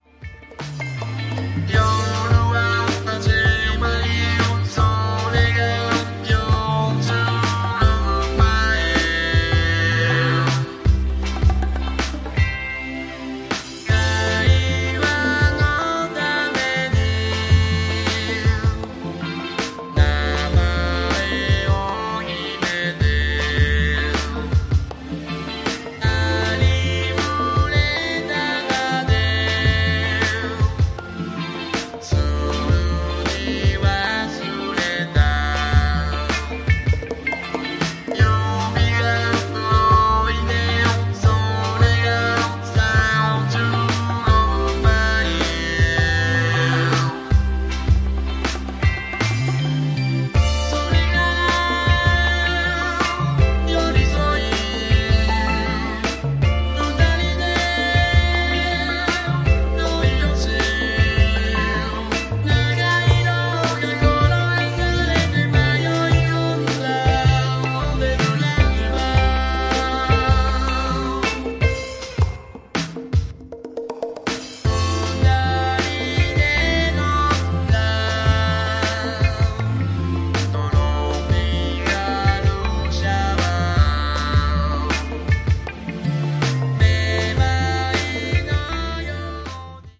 # POP# CLUB